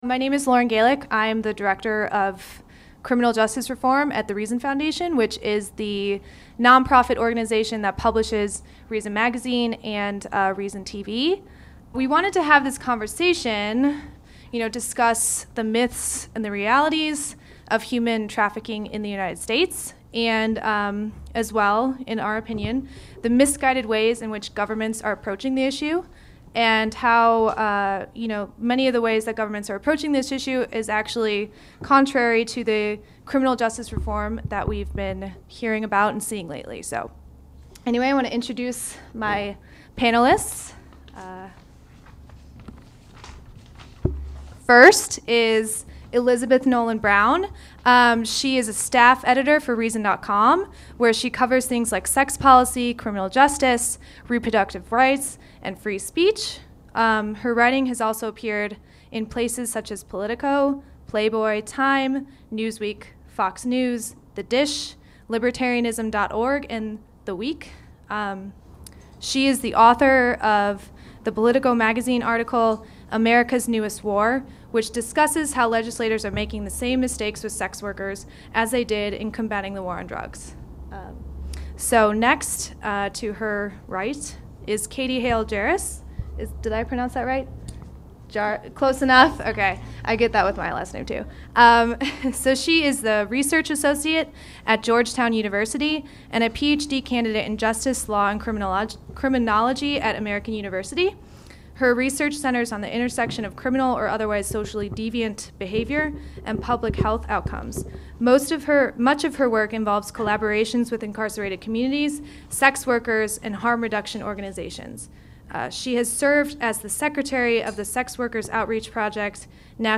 A Reason event on prostitution, sex trafficking, and the law